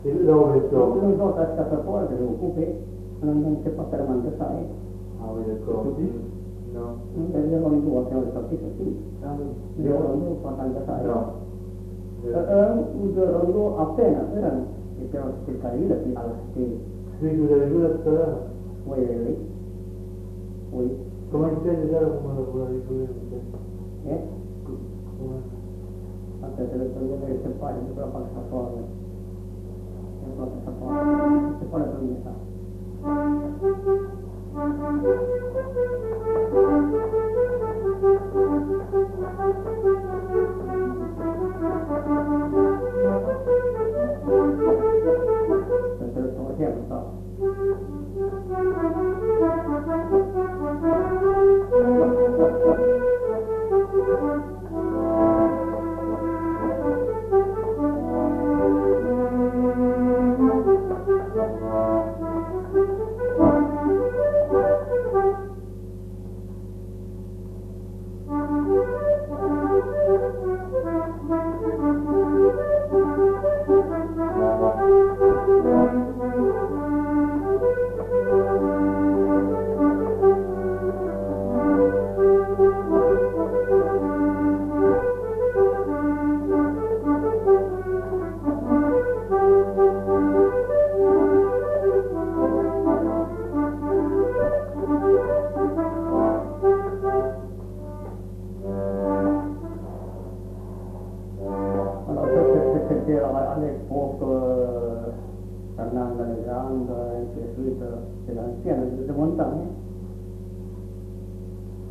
Aire culturelle : Marmandais gascon
Lieu : Escassefort
Genre : morceau instrumental
Instrument de musique : accordéon diatonique
Danse : quadrille